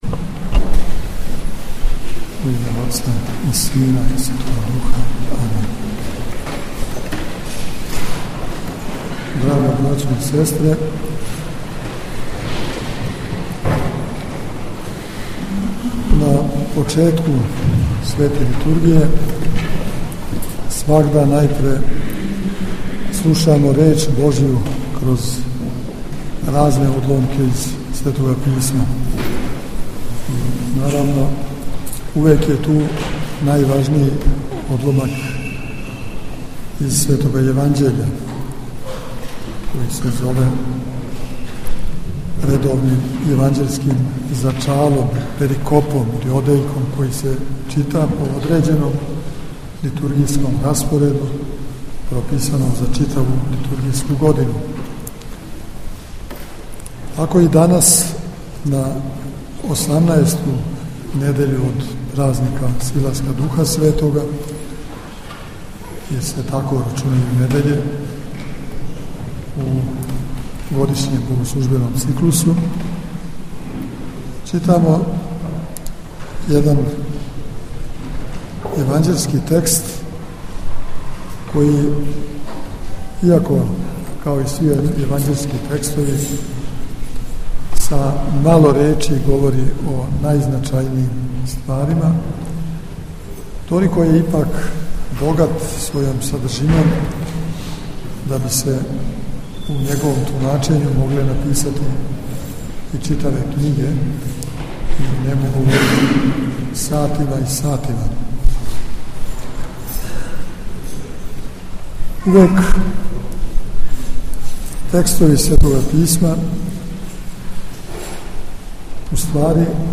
• Беседа Епископа Иринеја: